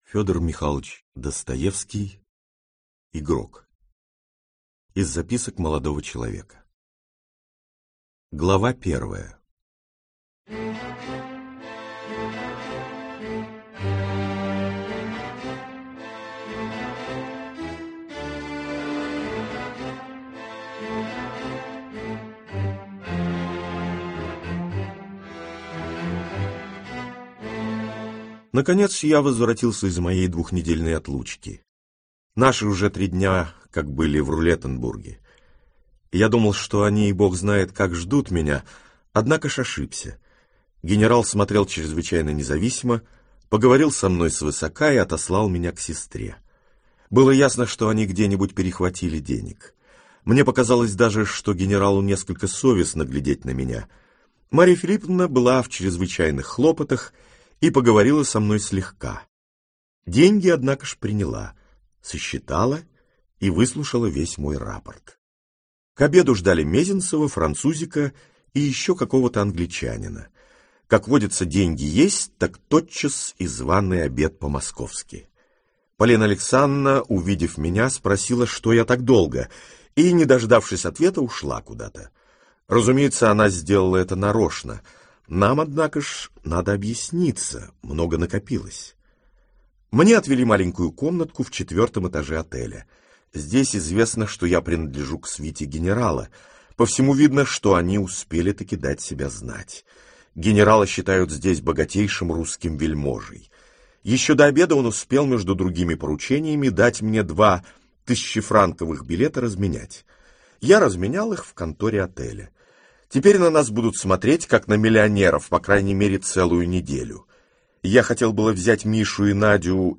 Аудиокнига Игрок | Библиотека аудиокниг
Прослушать и бесплатно скачать фрагмент аудиокниги